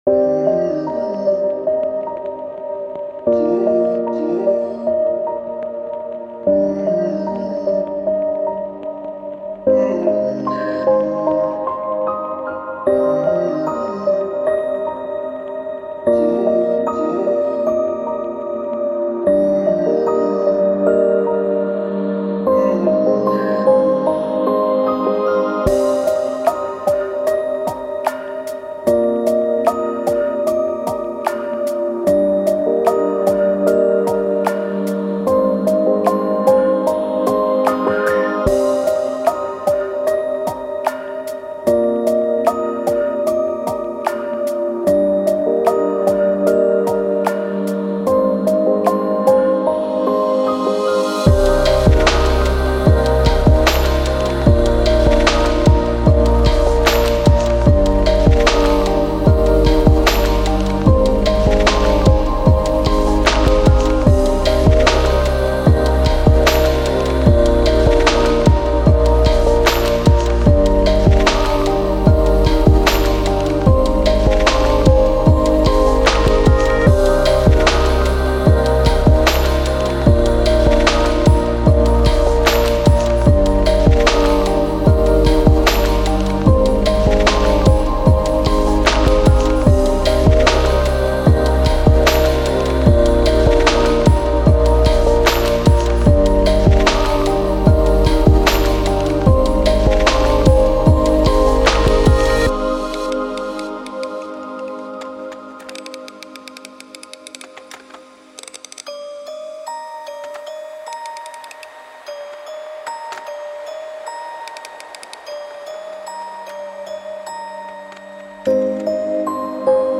электронная композиция